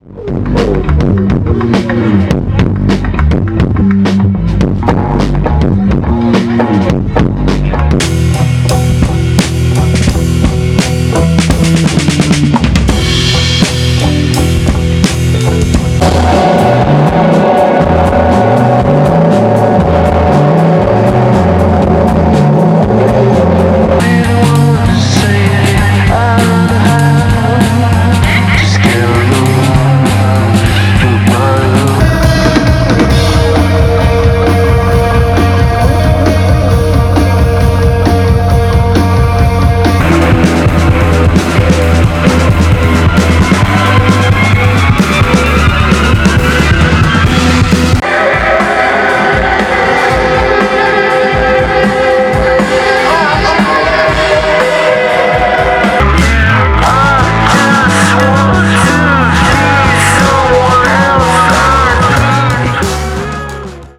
Three-piece live band: guitar, bass, drums, electronics.
Three-piece live band.
LONG HEAVY TRACKS, LOOPED LAYERS, NOISE, VOLUME. 45–75 MIN.